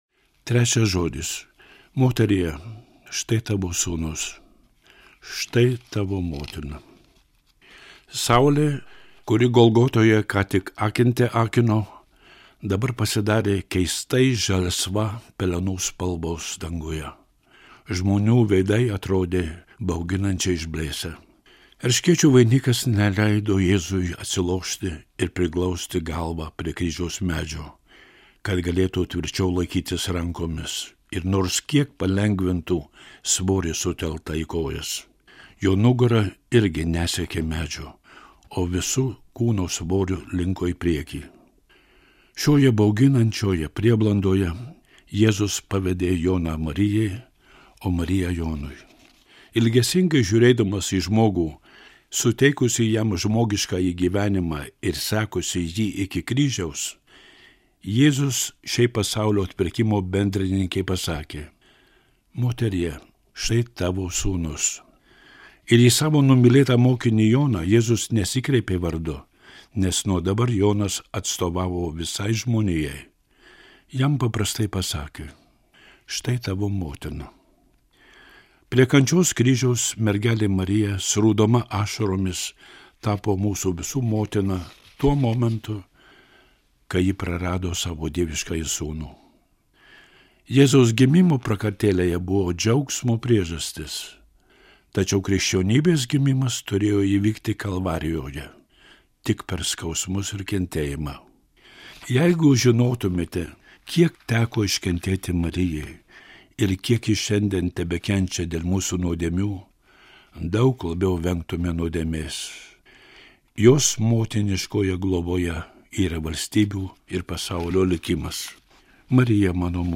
skaitovas / narrator